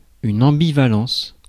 Ääntäminen
IPA: /ɑ̃.bi.va.lɑ̃s/ IPA: [ɑ̃.bi.va.lɑ̃ːs]